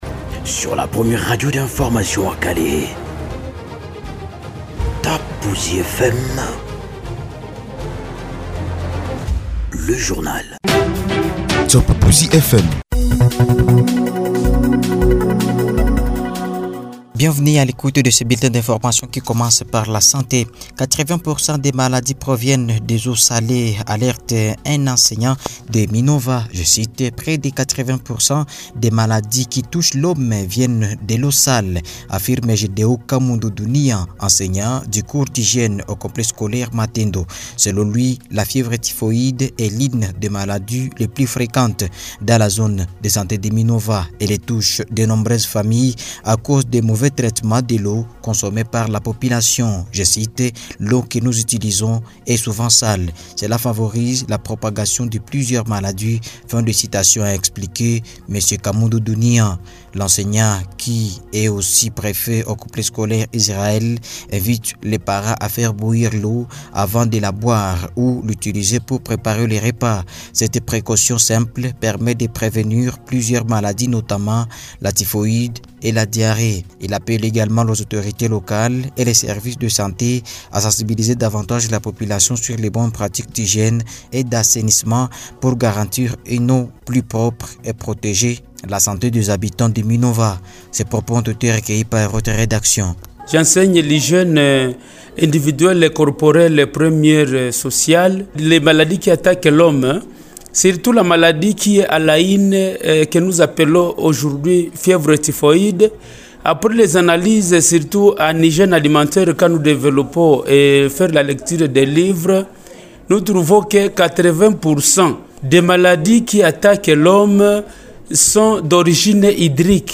Bulletin d’infos sur Top Buzi FM ce 6 novembre 2025
BULETIN-DINFORMATION-FR-CE-6-NOVE-OK-2025.mp3